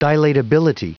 Prononciation du mot dilatability en anglais (fichier audio)
Prononciation du mot : dilatability